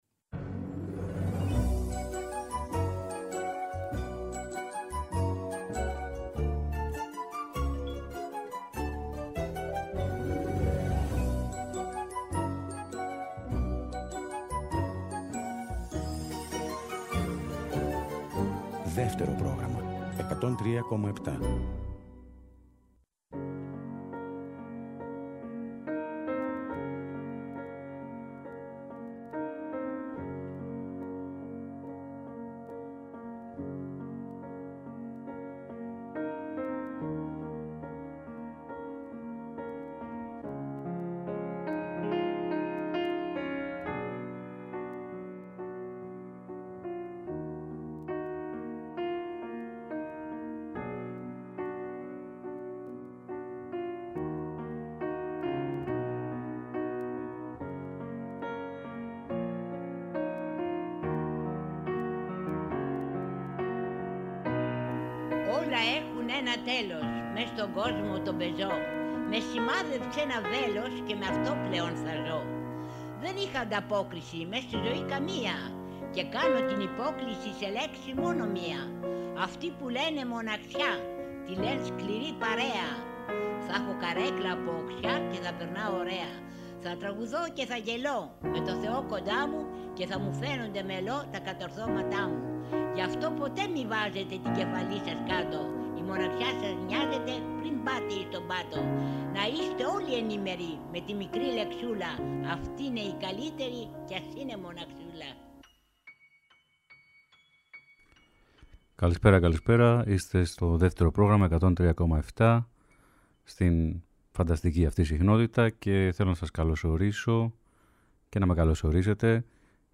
Η εκπομπή “Αντέλμα” με τον Στάθη Δρογώση στο μικρόφωνο, φέτος μετακομίζει κάθε Σάββατο στις 5 το απόγευμα για τη δεύτερη σεζόν της στο Δεύτερο Πρόγραμμα 103.7. Η σημερινή εκπομπή ξεκινά με ένα ειδικό αφιέρωμα σε τραγούδια που μπήκαν στους δεύτερους δίσκους καλλιτεχνών.
Στο τέλος κάθε εκπομπής θα παρουσιάζεται αποκλειστικά για τους ακροατές του Δεύτερου μία διασκευή για πιάνο και φωνή στην αρχή, στη συνέχεια θα μπουν και άλλα όργανα.